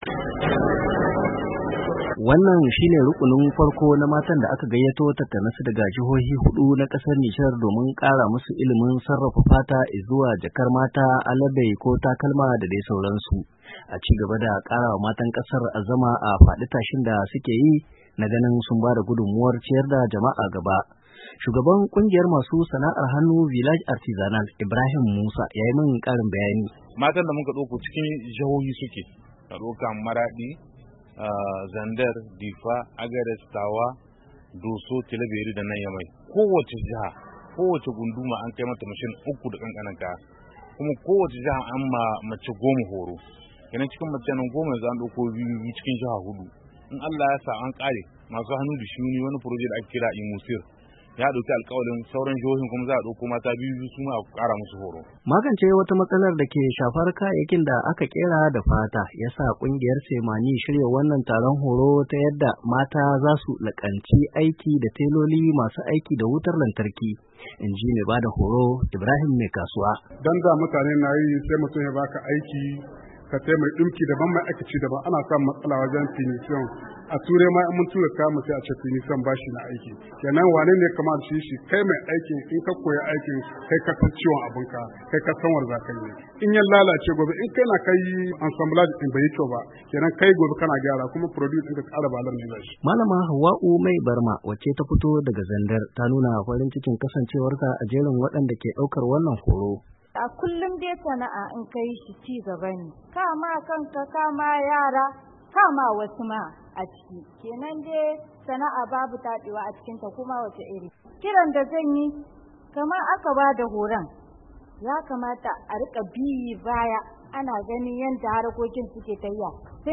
Domin jin cikakken rahoton saurari wakilinmu